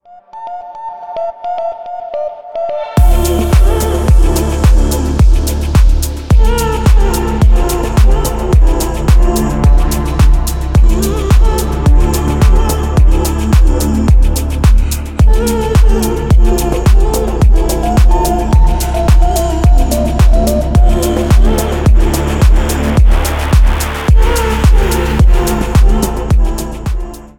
Клубные » Танцевальные